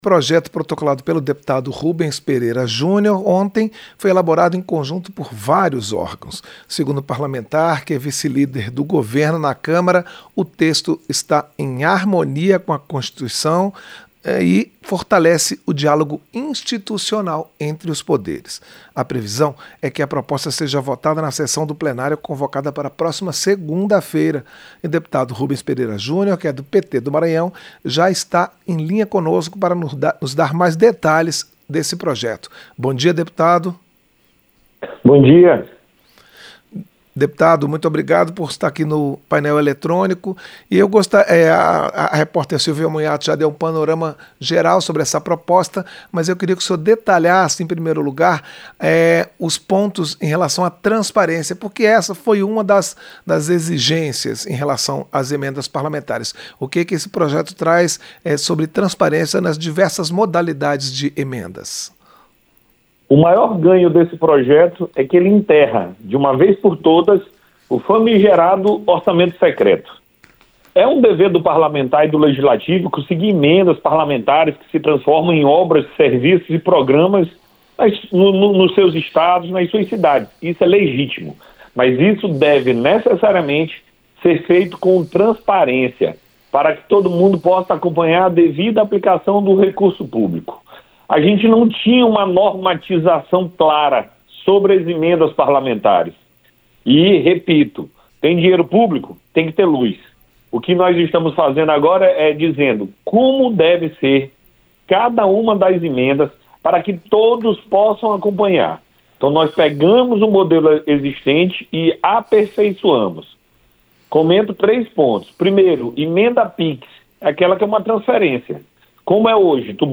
Entrevista - Dep. Rubens Pereira Júnior (PT-MA)